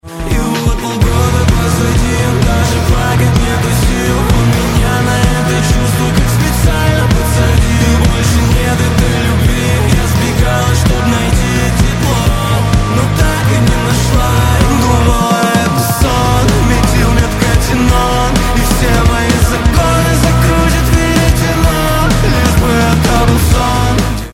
Alternative Rock
мрачные
русский рок